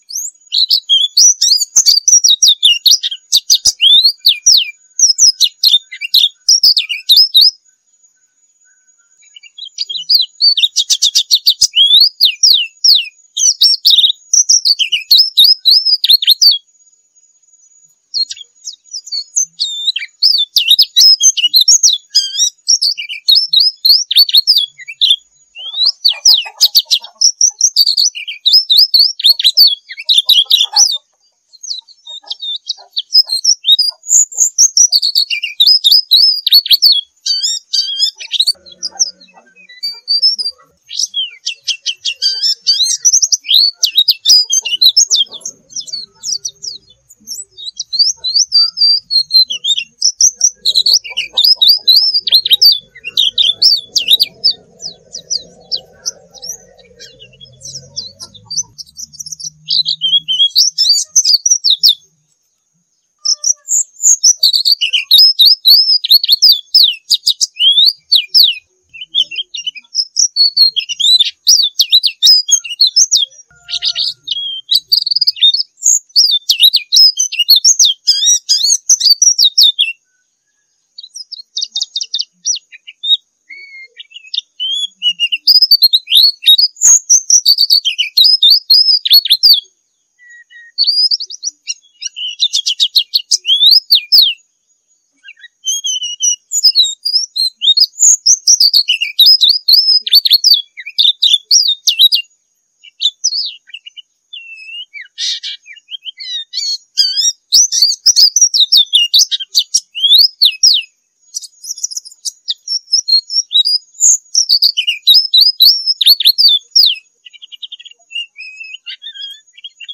Suara panggilannya juga agak berbeda,kadang terdengar menyerupai bunyi anak ayam. Volume bunyi burung ini keras dan tebal,agak ngebas. Selain itu,burung ini dikenal dengan bunyi yang ngetime ketika berkicau atau membutuhkan jeda waktu dari bunyi satu ke yang lain.
Suara Burung Pleci Dakung Bali
Suara-pleci-bali.mp3